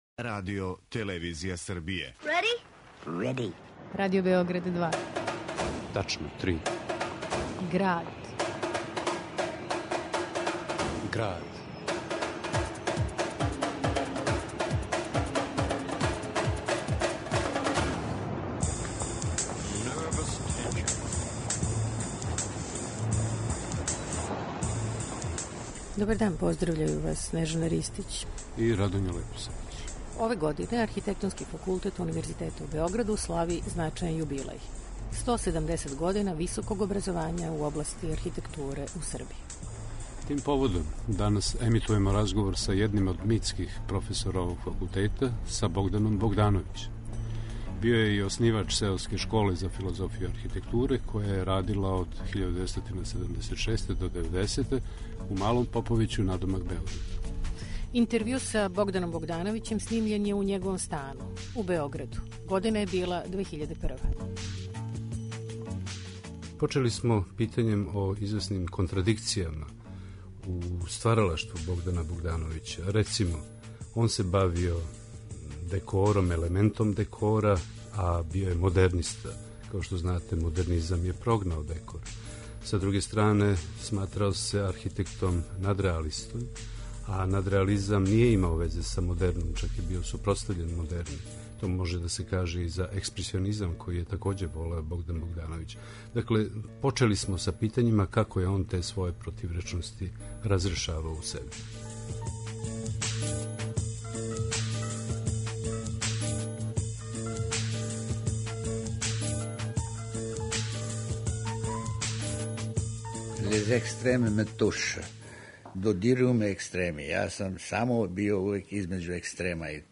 У Граду, поводом овогодишњег јубилеја београдског Архитектонског факултета: 170 година година високог образовања у области архитектуре у Србији, емитујемо интервју снимљен у Београду 2001: архитекта Богдан Богдановић говори о сопственим и нашим контроверзама...